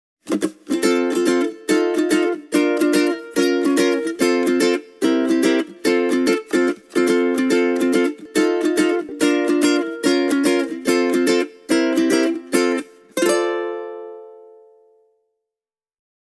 Tenor ukuleles often find their way into the hands of fingerpickers, many of whom prefer slightly wider nut widths, like 38 or 40 mm.
With the shimmed bridge saddle the V4-T Sun displayed a healthy, warm and big tone.